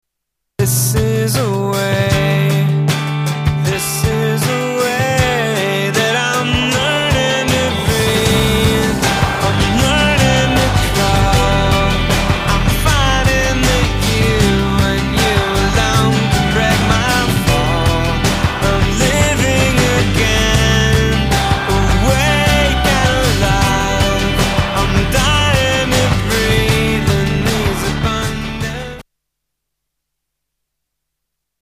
STYLE: Rock
with a laconic vocal-cum-prayer